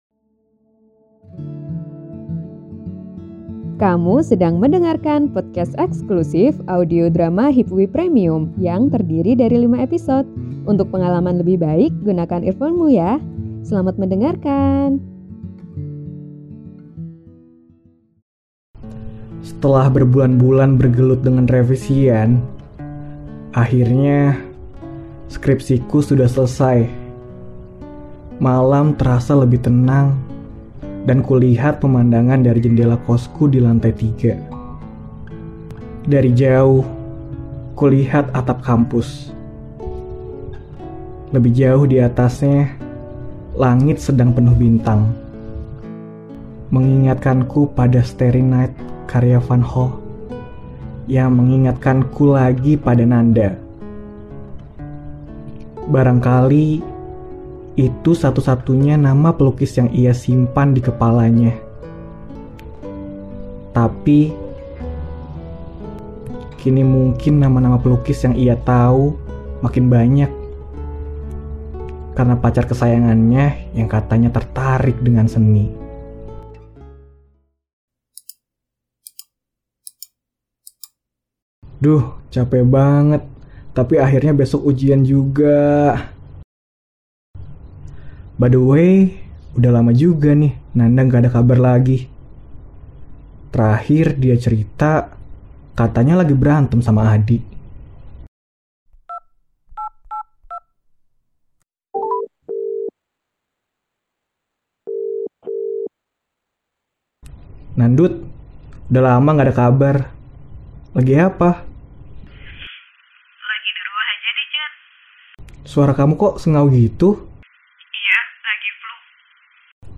[Audio Drama] Sebatas Kembali – Episode 4